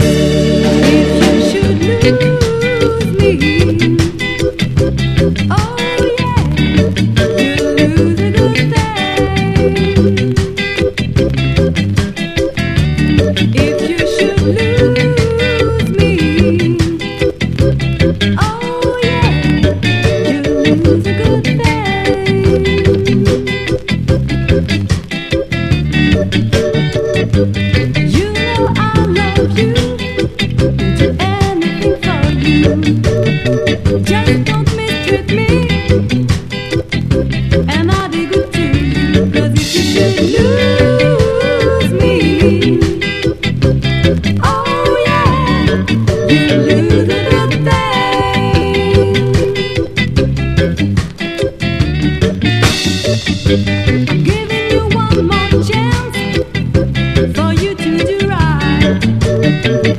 強力な歌モノ・スカも収録しています！